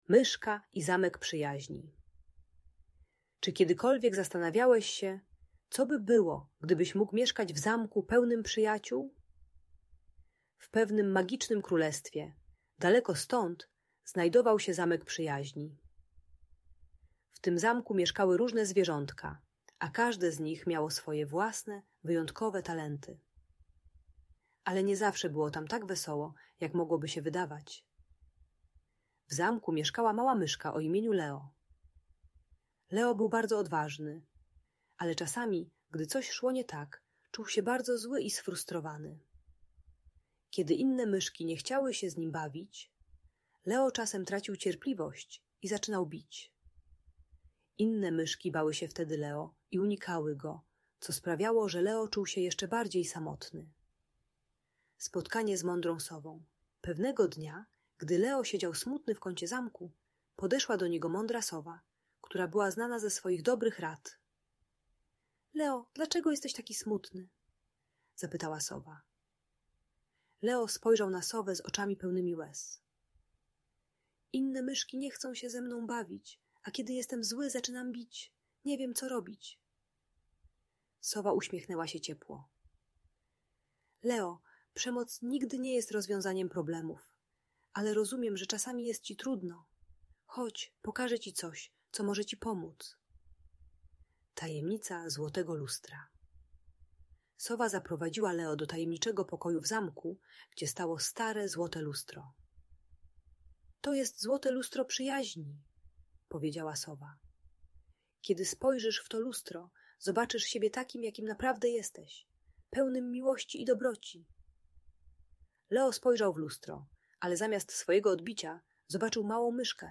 Opowieść o Myszce i Zamku Przyjaźni - Audiobajka dla dzieci